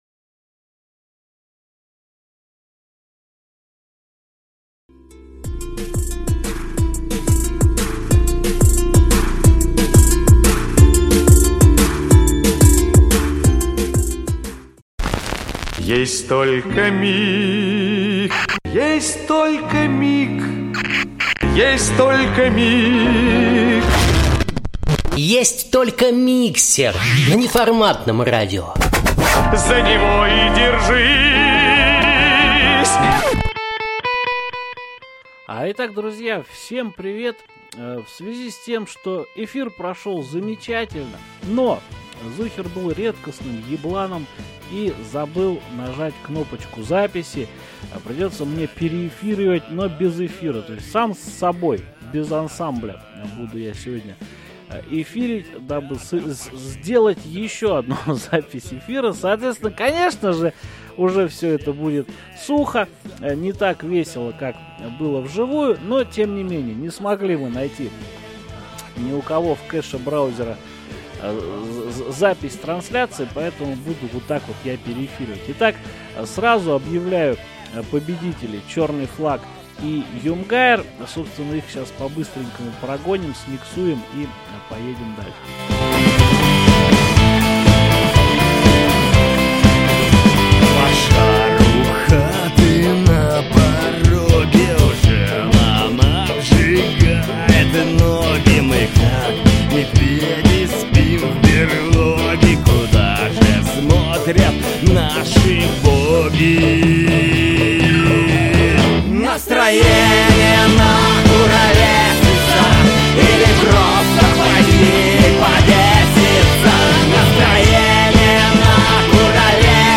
Пришлось заново переозвучивать всю программу - ну сами понимаете - не то это уже))) Но тем не менее...